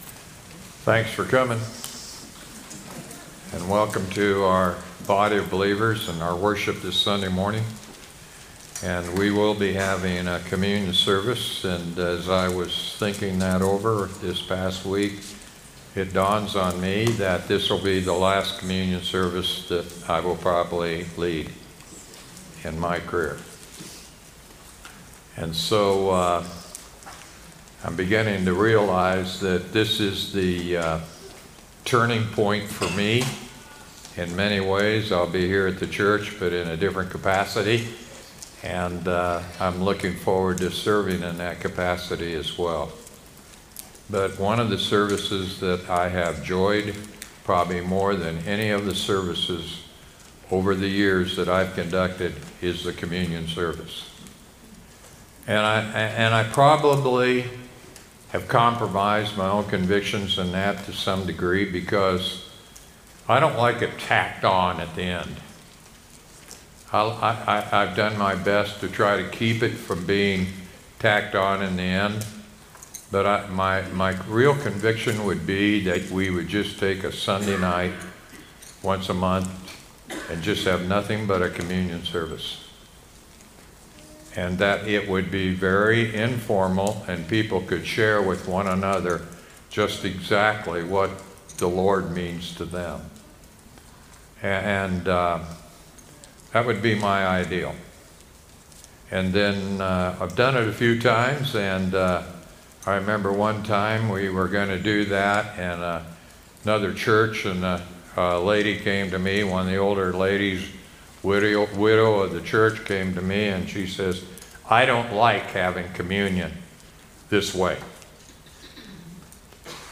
sermon-9-15-24.mp3